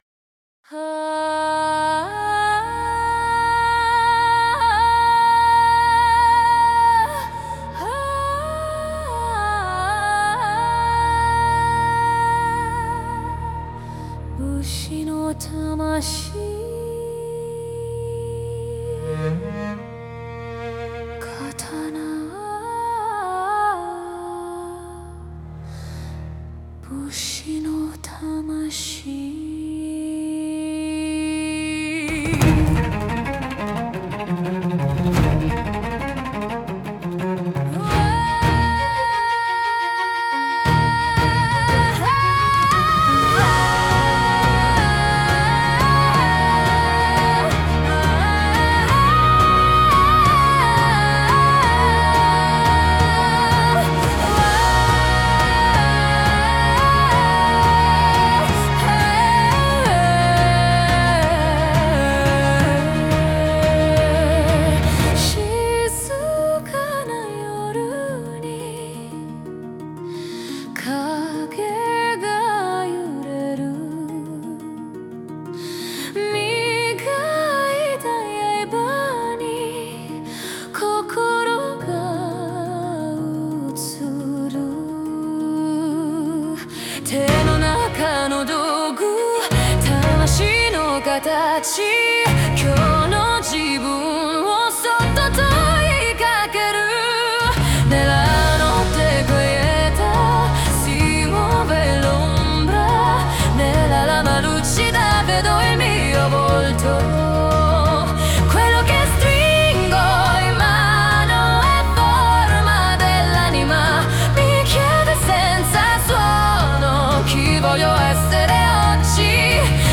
La musica non resta uguale: si trasforma.
Cambia stato, cambia respiro, cambia frequenze.
La prima parte del brano utilizza frequenze basse (396 Hz e 417 Hz).
La seconda sezione si apre con 432 Hz e 528 Hz, frequenze usate in molte pratiche meditative.
Qui le due lingue – italiano e giapponese – si alternano come un’onda.
Il suono diventa più ampio, più sicuro, come una mente che ha ritrovato la direzione.
L’ultima parte è la più sottile: 852 Hz e un ritorno a 432 Hz.
“Spada di Luce” funziona come una meditazione attiva.